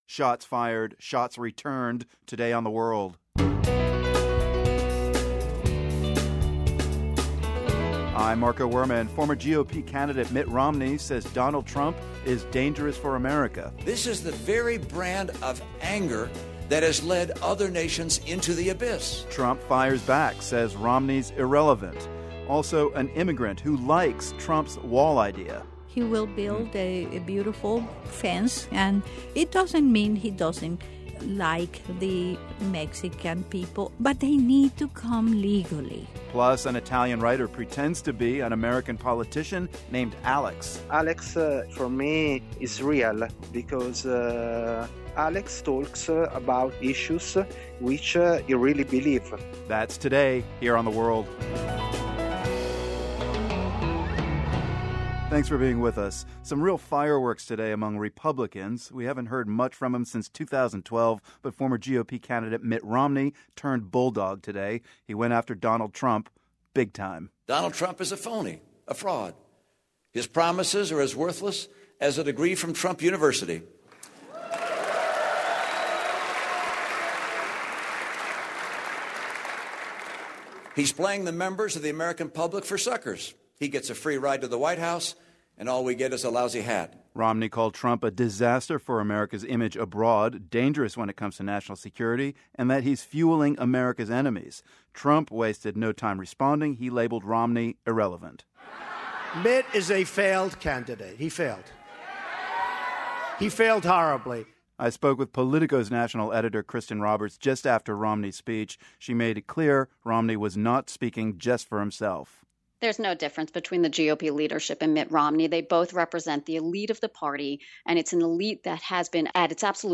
Then, staying on the political beat, we'll hear from a Trump supporter in Florida.